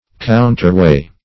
Search Result for " counterweigh" : The Collaborative International Dictionary of English v.0.48: Counterweigh \Coun`ter*weigh"\ (-w[=a]"), v. t. To weigh against; to counterbalance.